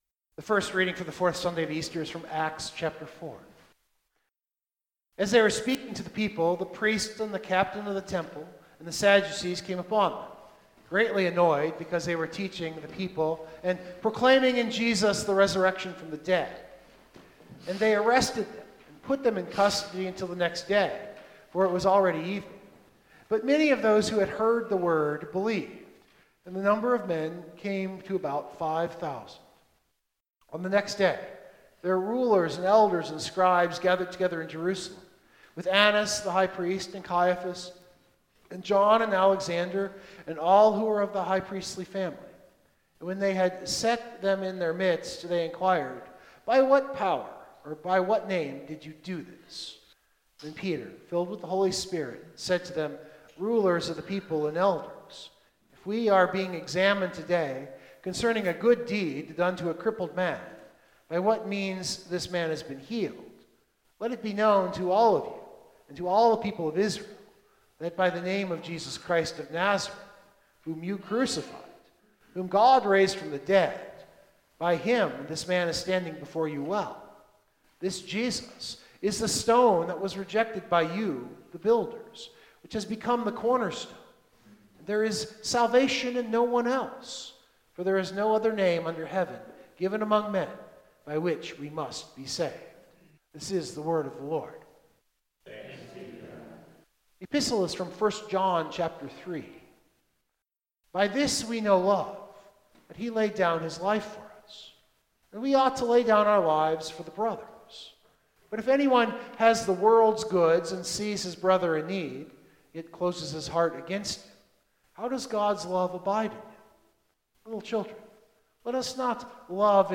Biblical Text: John 10:11-18 Full Sermon Draft